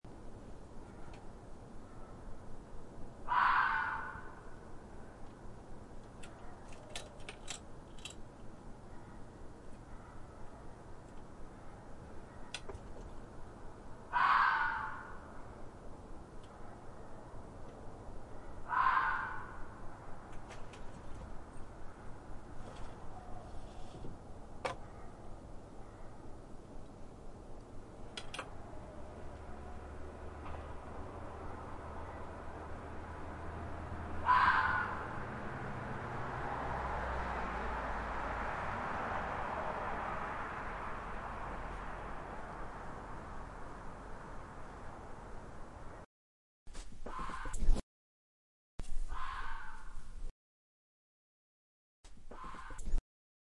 Fox Scream Efecto de Sonido Descargar
Animal Sounds Soundboard209 views